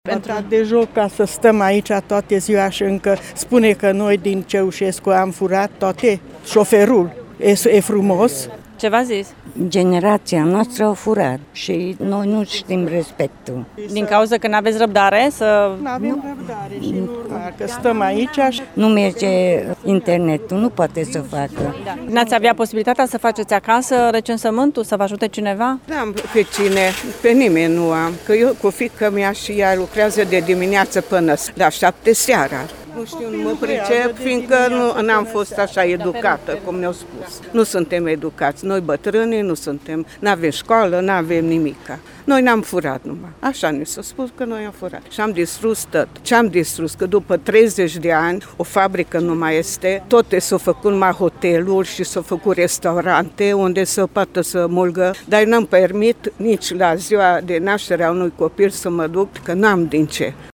Și la autobuzul de autorecenzare era nelipsitul rând format, în general, din pensionari. Oamenii sunt revoltați că stau și azi inutil la rând ore întregi, iar unii pentru a patra sau a cincea zi, iar în plus au fost jigniți și de șofer: